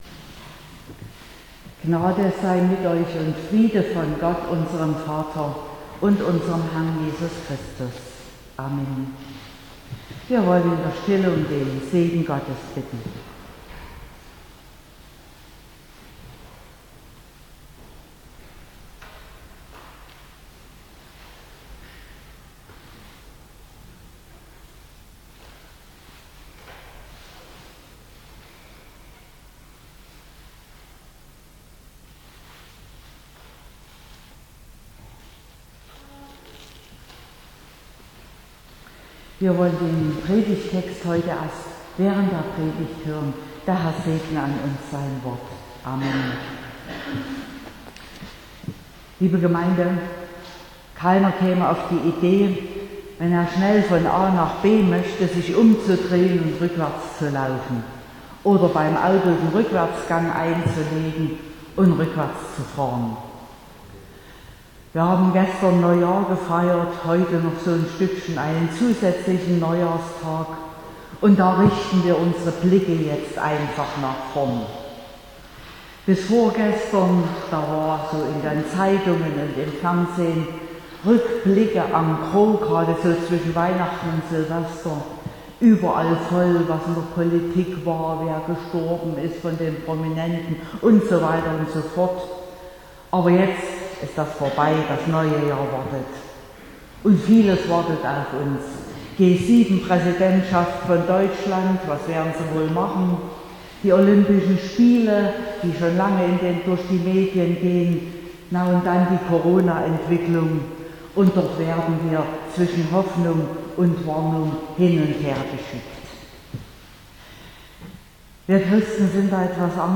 02.01.2022 – Gottesdienst
Predigt (Audio): 2022-01-02_Des_Menschen_Herz_erdenkt_sich_seinen_Weg__Gott_lenkt_seinen_Schritt.mp3 (25,3 MB)